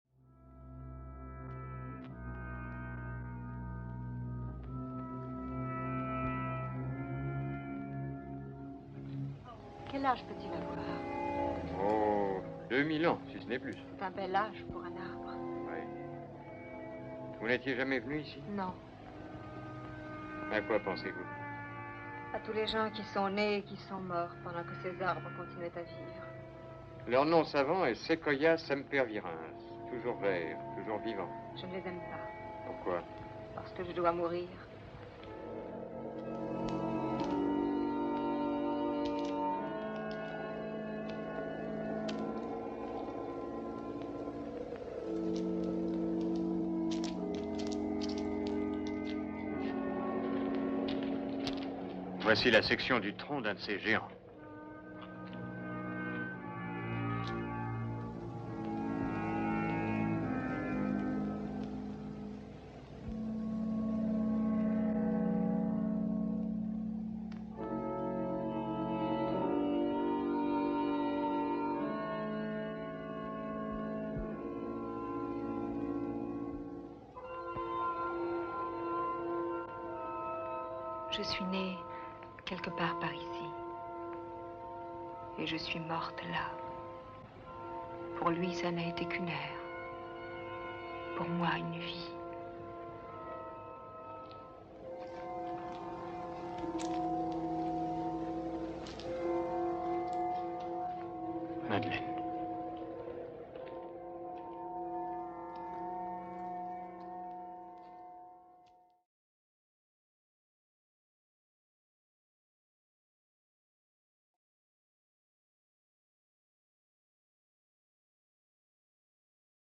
Le son des pas du visiteur se mêle aux voix des acteurs du film Vertigo d’Alfred Hitchcock (1958 – titre français : Sueurs froides).